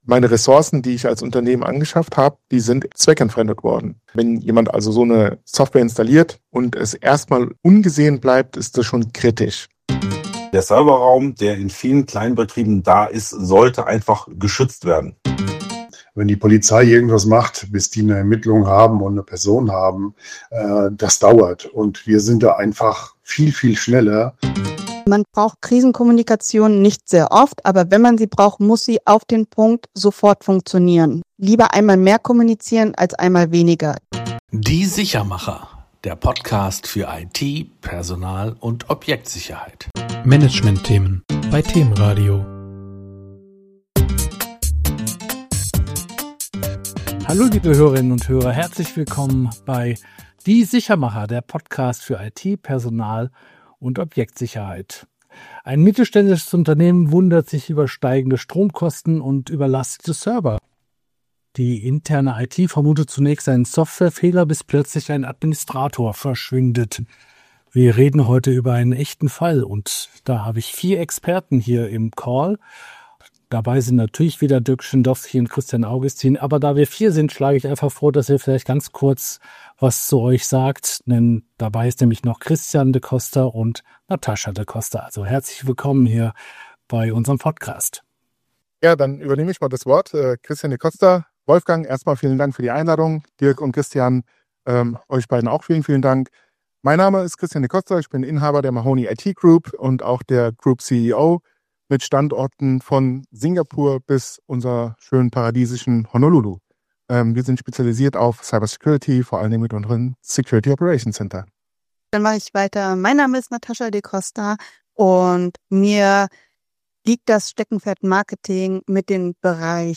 Bitcoin-Mining auf Firmenservern – Wenn Mitarbeiter zu Tätern werden Ein interner Administrator nutzt die IT-Systeme seines Unternehmens für privates Krypto-Mining – unbemerkt, über Monate. Das Ergebnis: hohe Kosten, forensische Untersuchungen und eine massive Vertrauenskrise. Zu diesem echten Fall können wir in dieser Folge gleich vier Experten hören: